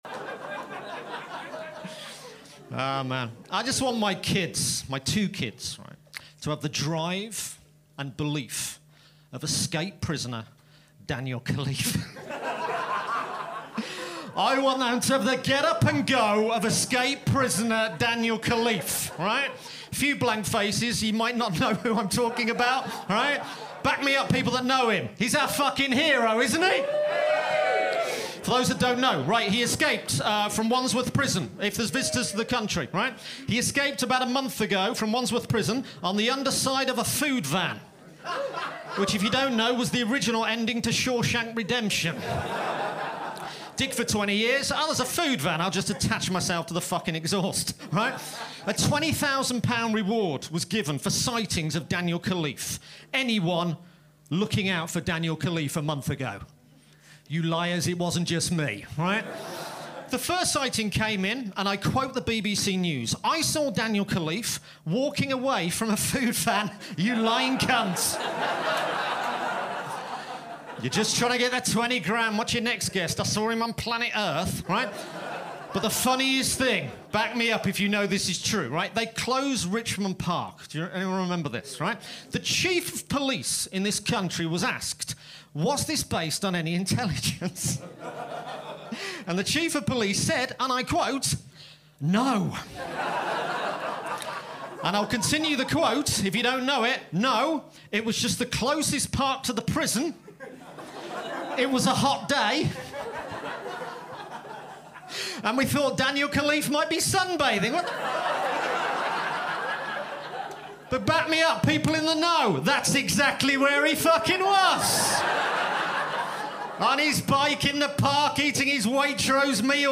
Season 4 is a limited season of shorter bits mainly without piano
In at 5, and back in prison, it's Daniel Khalife. Recorded Live at Backyard Comedy Club 2023.